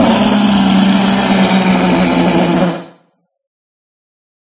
Monster8.ogg